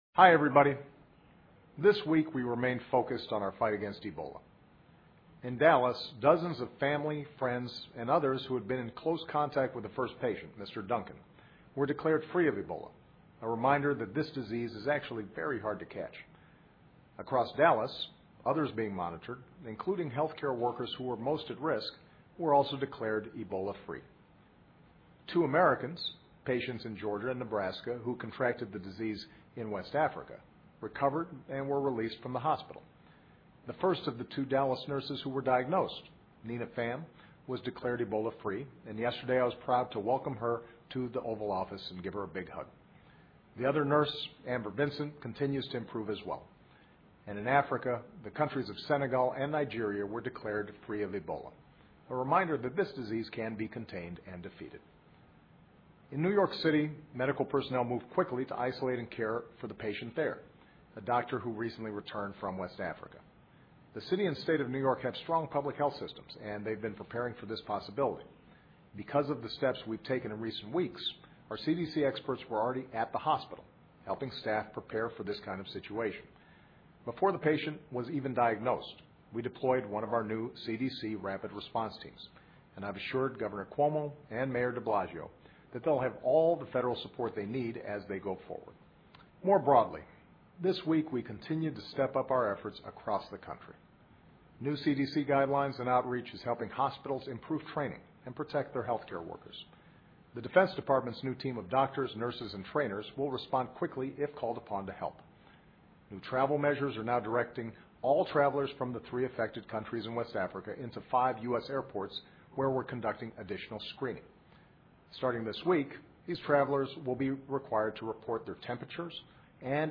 奥巴马每周电视讲话：总统呼吁全力抗击埃博拉病毒 听力文件下载—在线英语听力室